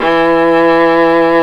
Index of /90_sSampleCDs/Roland - String Master Series/STR_Viola Solo/STR_Vla1 % + dyn